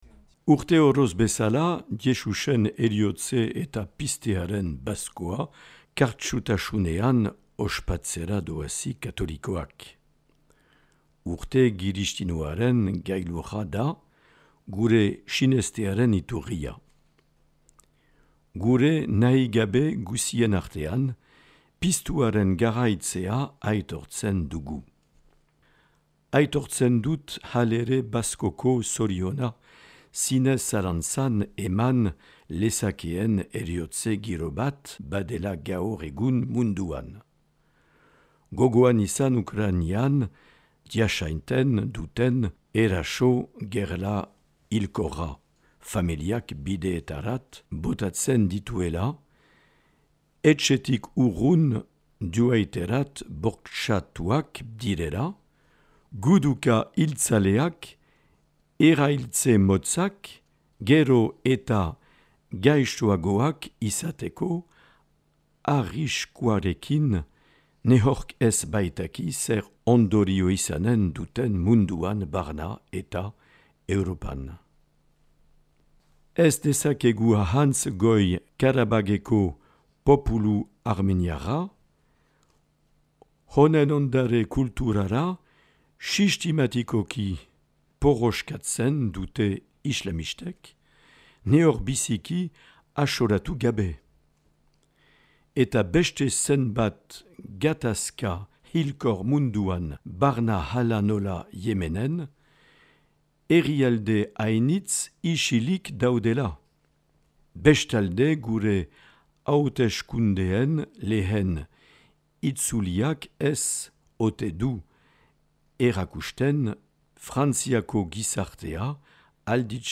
Bazko Mezua 2022 - Marc Aillet, Baionako apezpikua